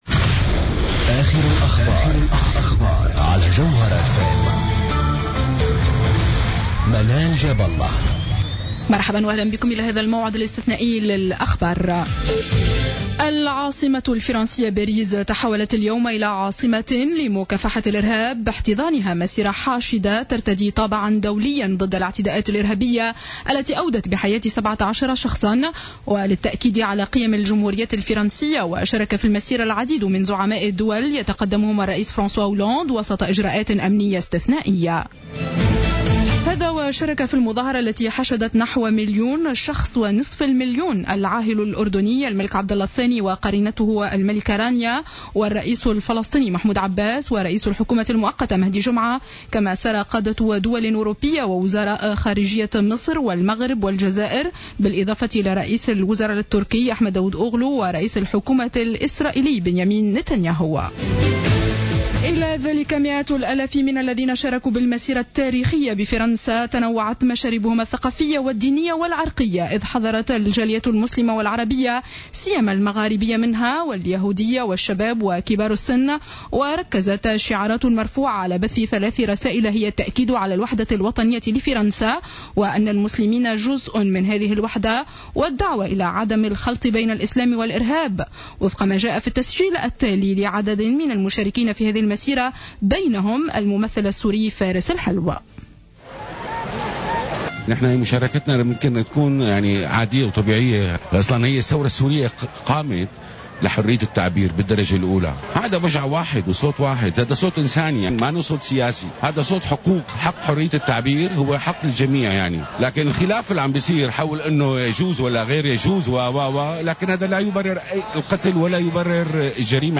اخبار السابعة مساء ليوم الأحد 11-01-14